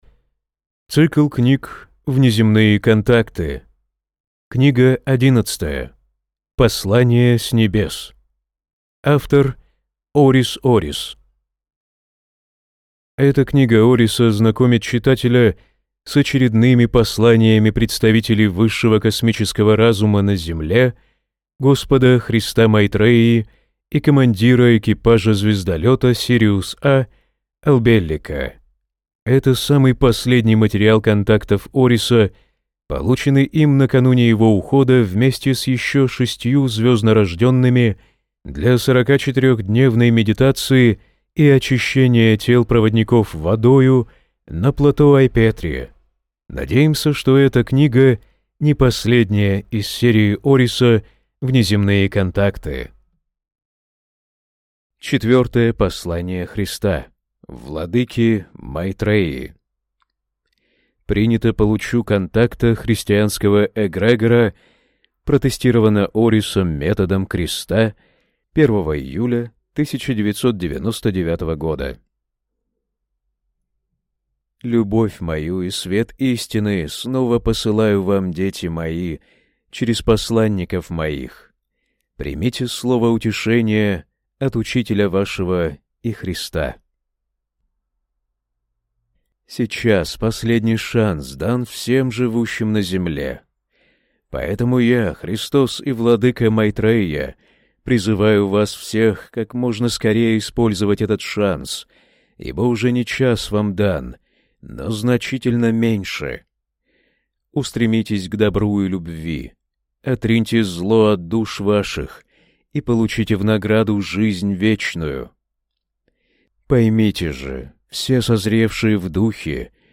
Аудиокнига Послания с Небес | Библиотека аудиокниг